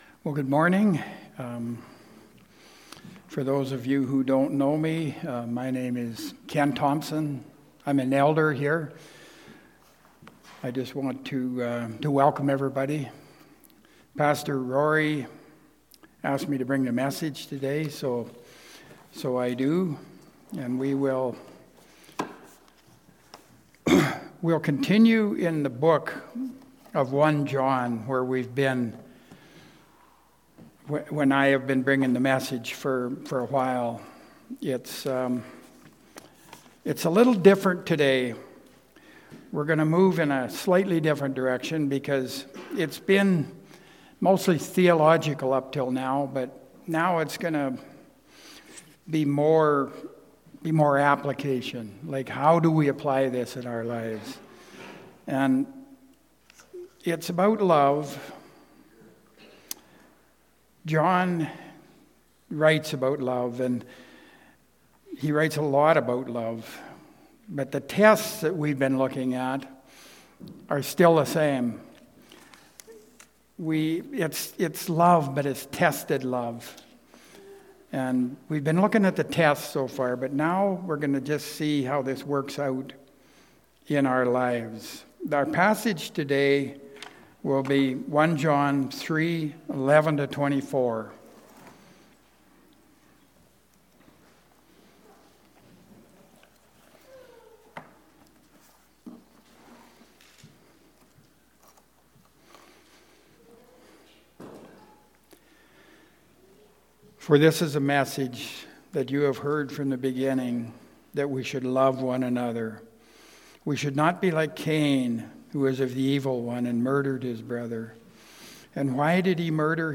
Coram Deo Sermons
New Sermons are released and updated weekly.